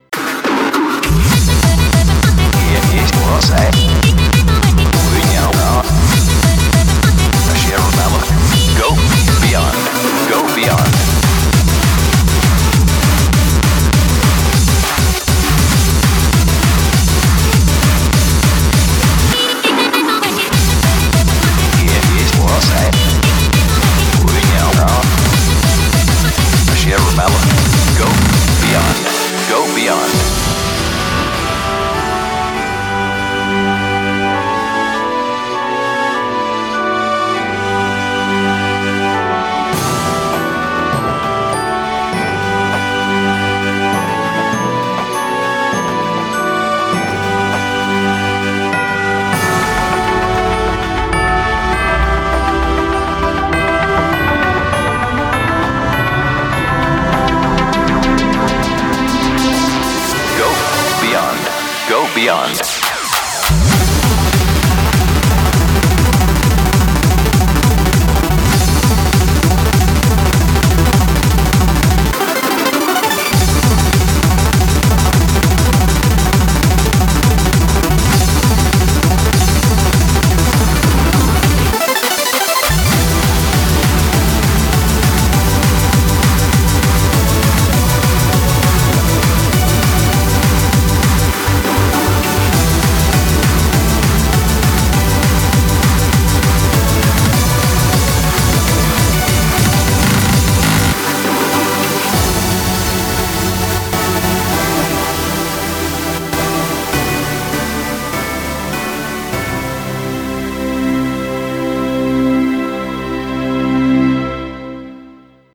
BPM100-200
Audio QualityCut From Video